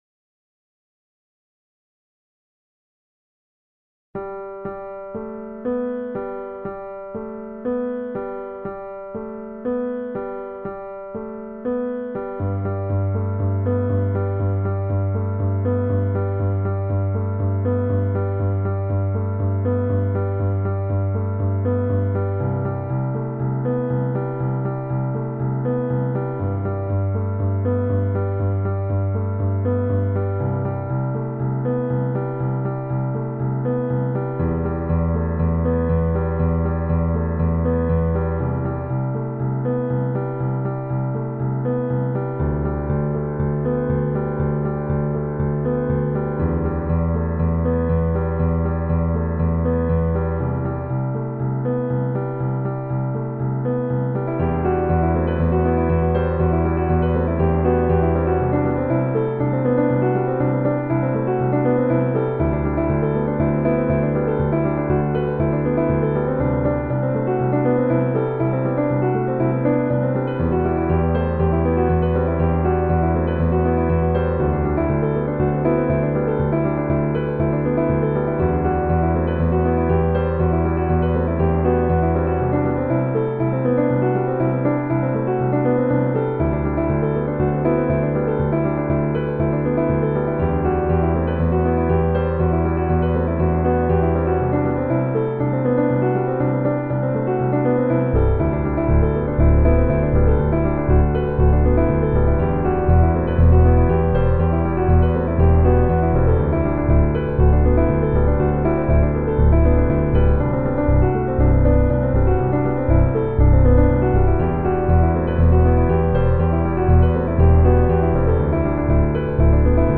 Then you'll increase the energy with the drum section: